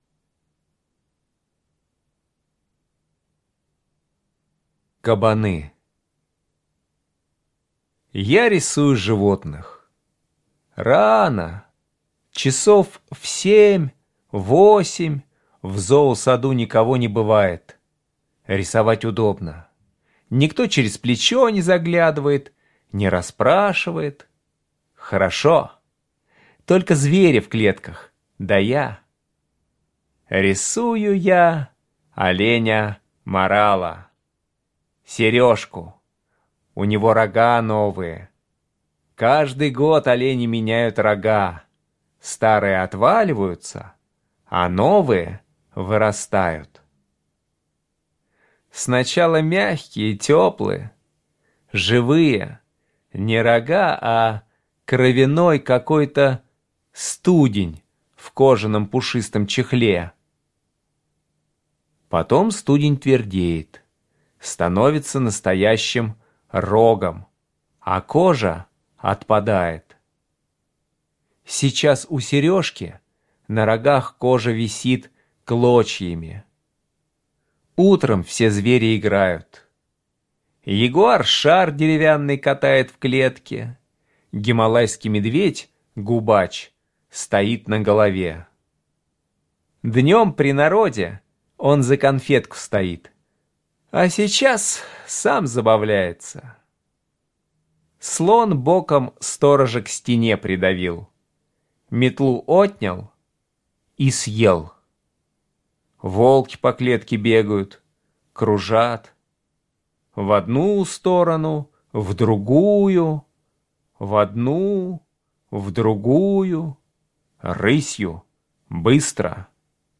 Аудиорассказ «Кабаны»